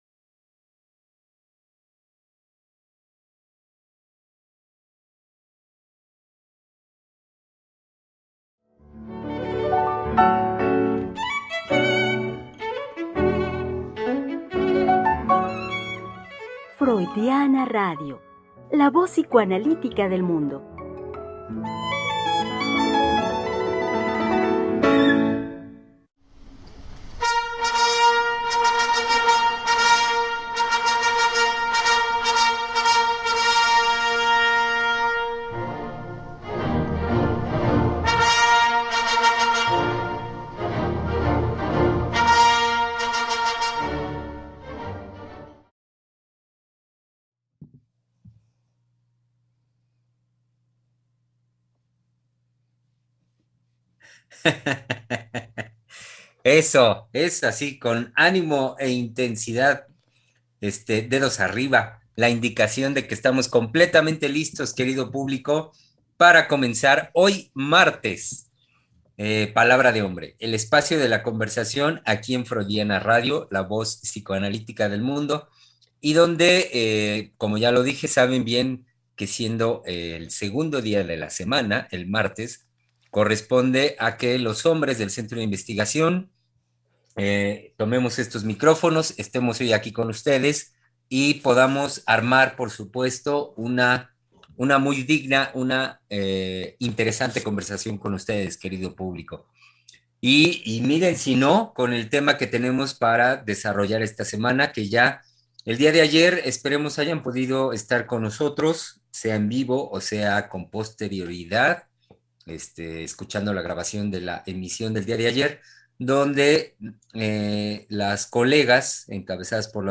Programa transmitido el 23 de noviembre del 2021.